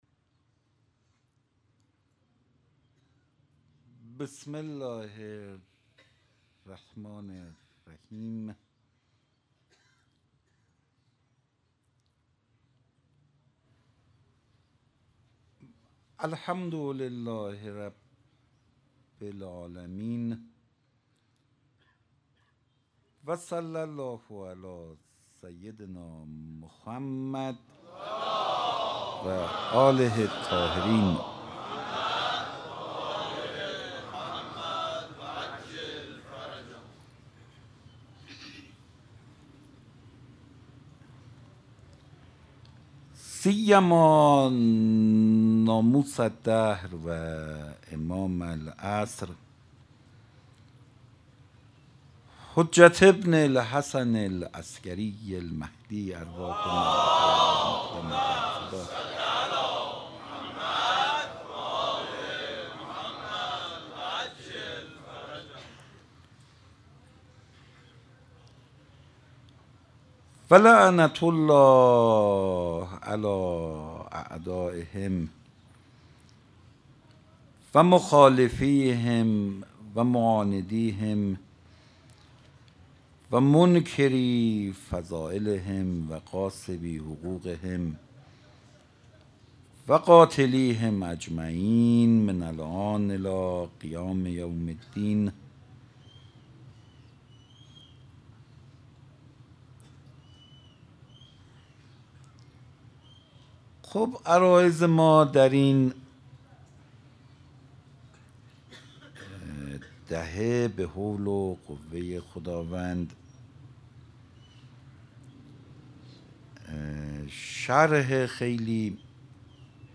سخنرانی روز هفتم